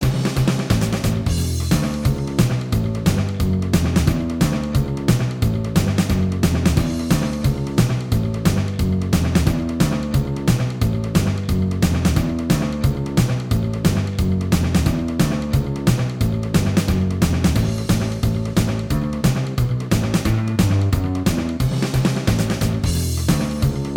Minus Guitars Rock 'n' Roll 3:01 Buy £1.50